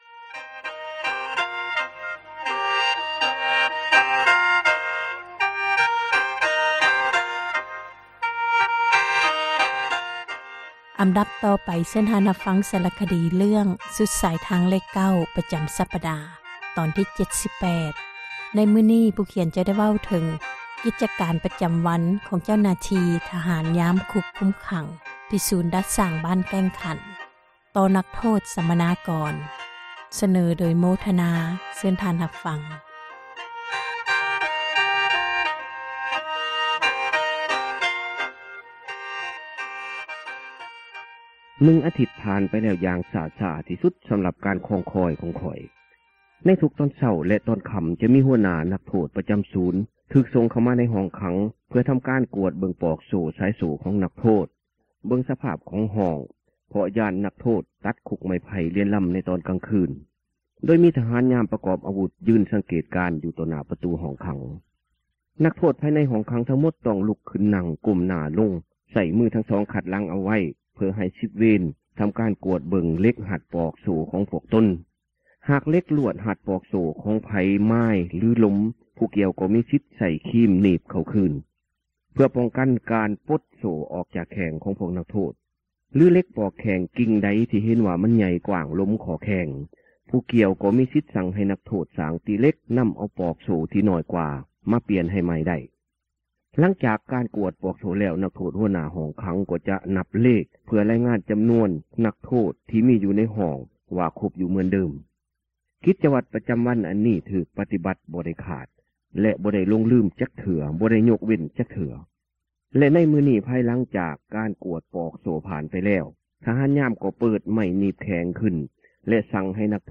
ສາຣະຄະດີ ເຣື້ອງ ສຸດສາຍທາງເລຂ 9 ຕອນທີ 78 ໃນມື້ນີ້ ຜູ້ຂຽນ ຈະເວົ້າເຖິງ ກິຈວັດ ປະຈຳວັນ ຂອງທະຫານຍາມ ຄຸກຄຸມຂັງ ທີ່ ສູນດັດສ້າງ ບ້ານແກ້ງຄັນ ຕໍ່ນັກໂທດ ສັມມະນາກອນ ສເນີໂດຍ